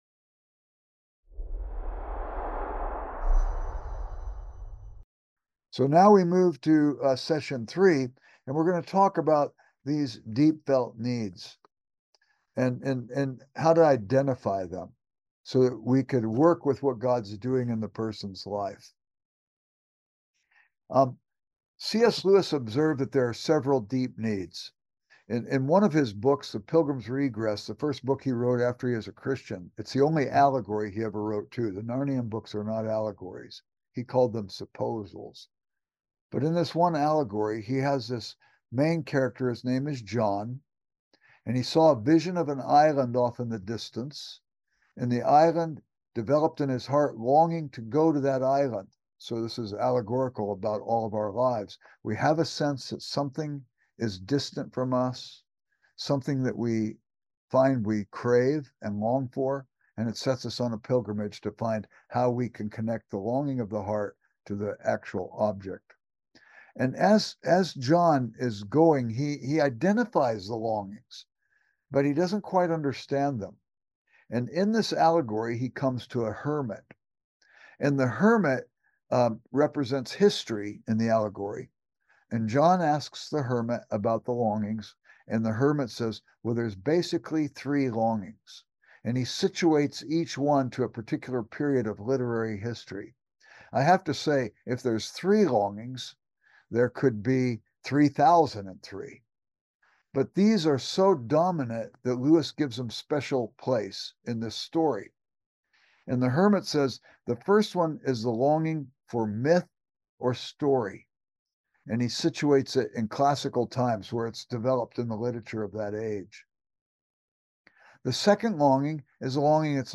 Event: Master Class Topic